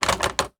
Toilet Paper Dispenser 4 Sound
household